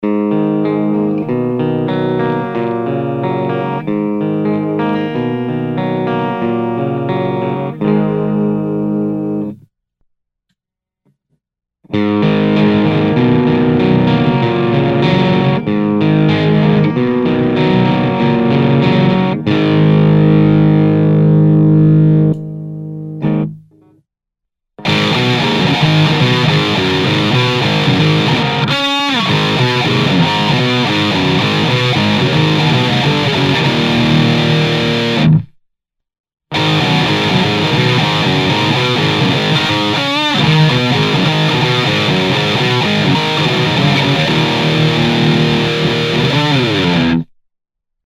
オーバドライブはGAIN7 Bass6 Middle6 Treble7プリアンプブースターON時はGAIN5
クリーンはGAIN4 Bass５ Treble5
ピックアップはEMG81です。
良くも悪くも真空管の音です。